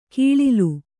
♪ kīḷilu